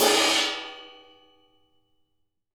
Index of /90_sSampleCDs/Sampleheads - New York City Drumworks VOL-1/Partition A/KD RIDES
CHOKE2    -L.wav